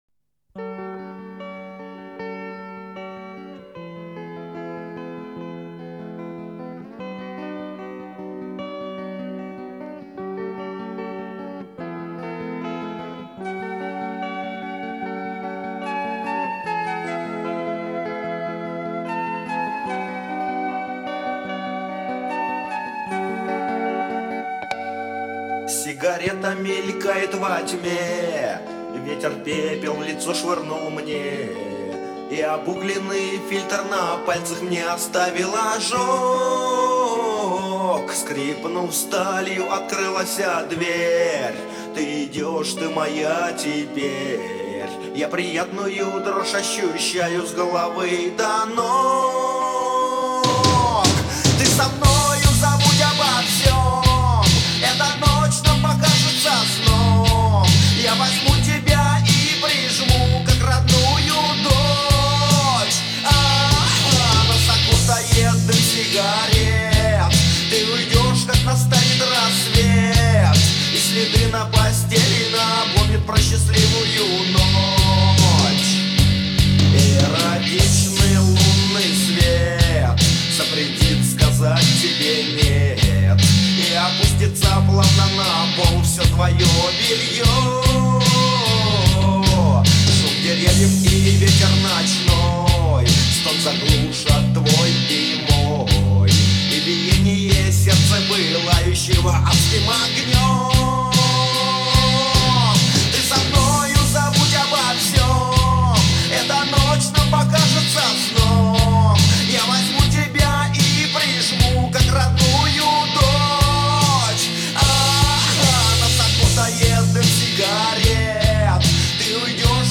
Русский Рок